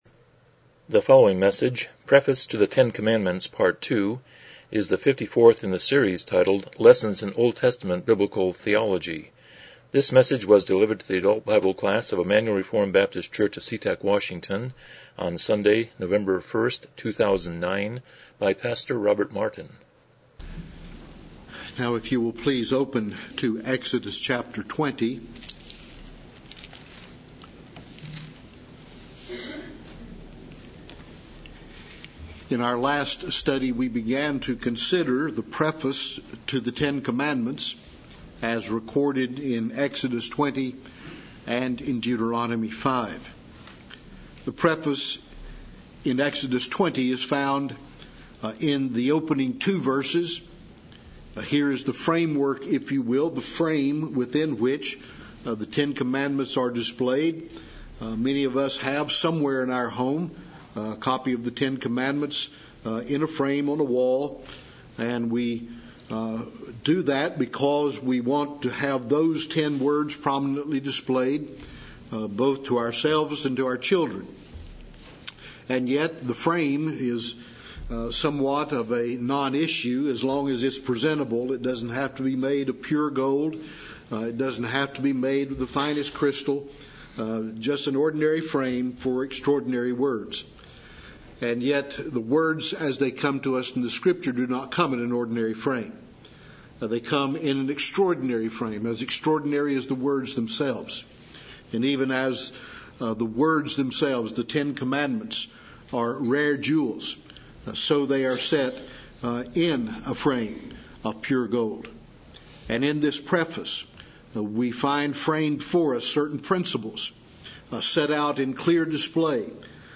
Series: Lessons in OT Biblical Theology Service Type: Sunday School